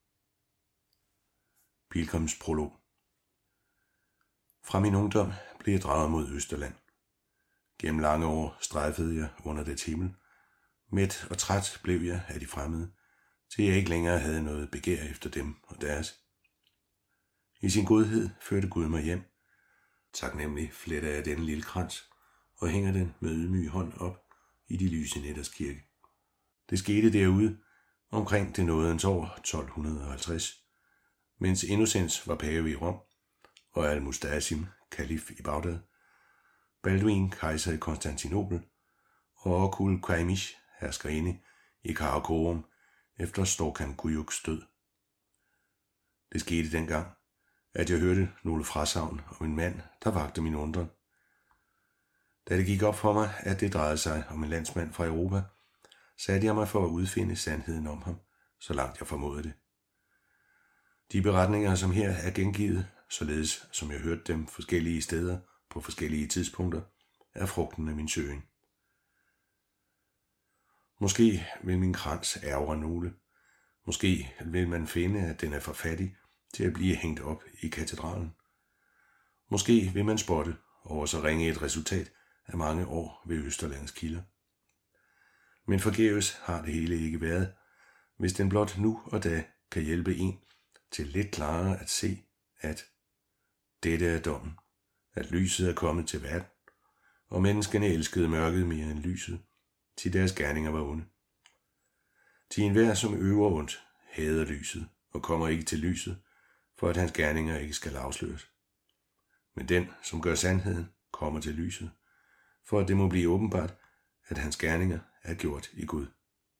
Den gyldne rose af Poul Hoffmann- MP3-lydbog